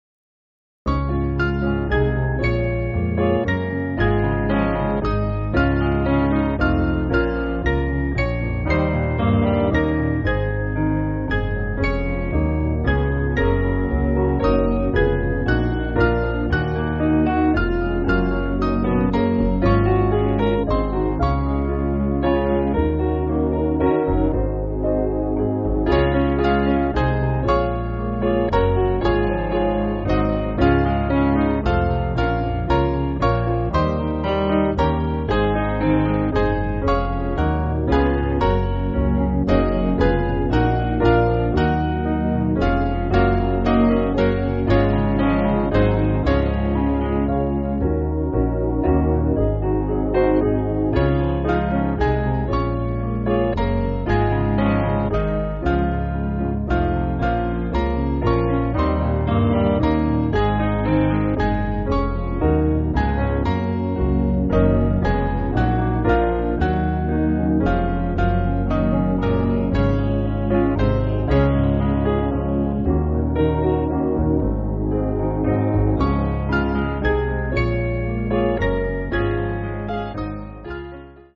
Mainly Piano
(CM)   5/Db-D